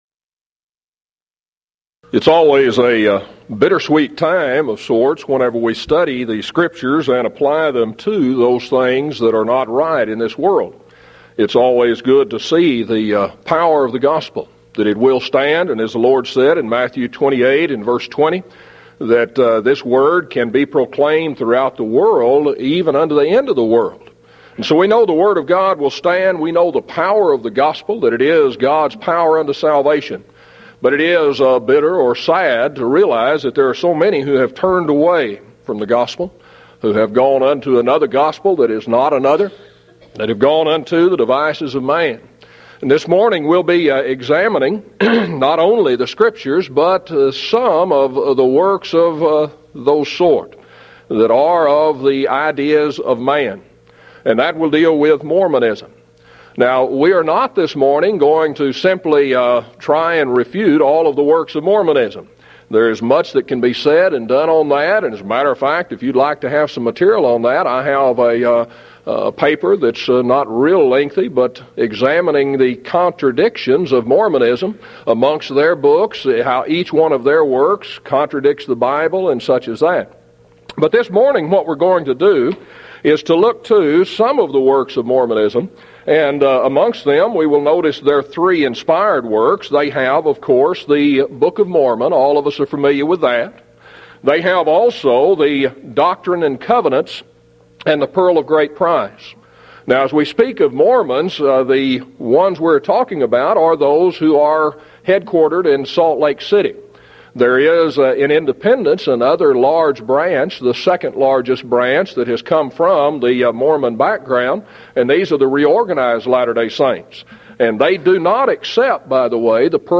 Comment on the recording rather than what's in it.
Event: 1995 Mid-West Lectures